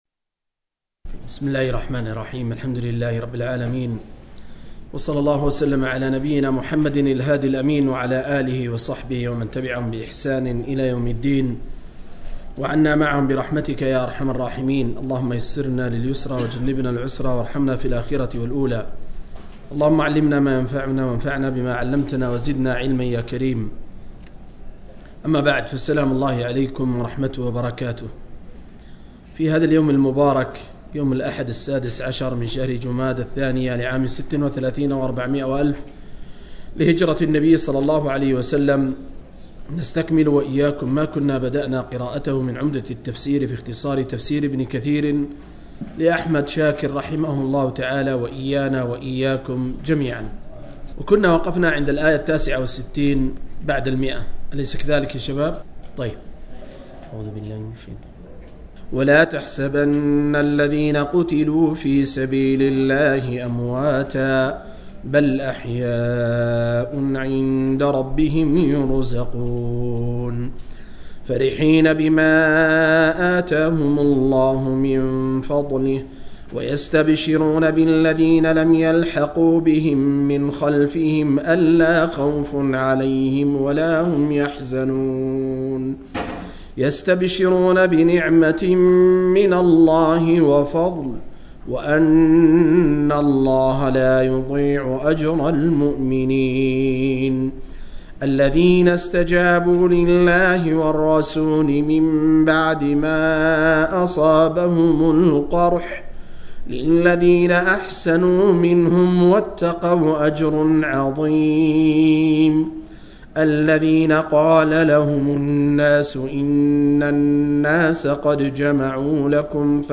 077- عمدة التفسير عن الحافظ ابن كثير رحمه الله للعلامة أحمد شاكر رحمه الله – قراءة وتعليق –